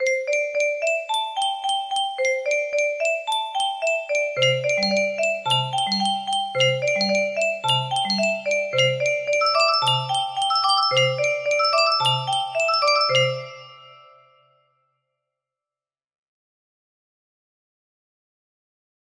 C–D–E–G–A music box melody